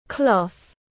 Vải sợi tiếng anh gọi là cloth, phiên âm tiếng anh đọc là /klɔːθ/.
Cloth /klɔːθ/